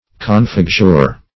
Confixure \Con*fix"ure\ (?; 135), n. Act of fastening.